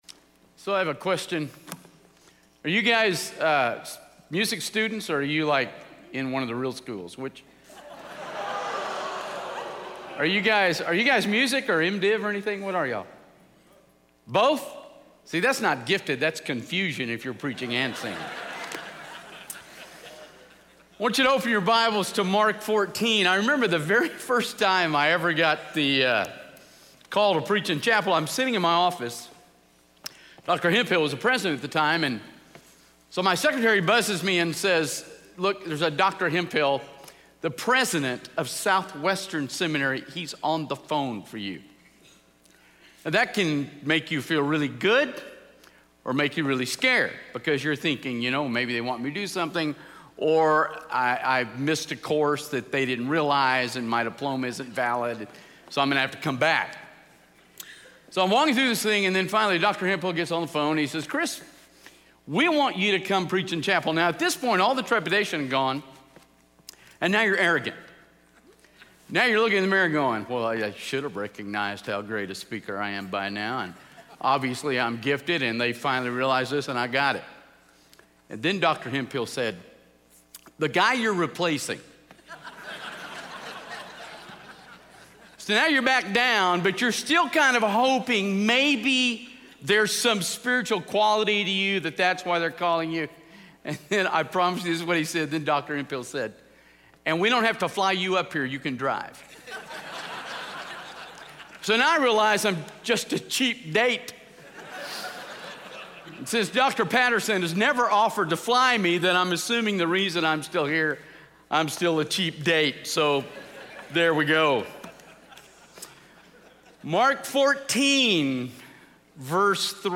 in SWBTS Chapel